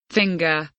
finger kelimesinin anlamı, resimli anlatımı ve sesli okunuşu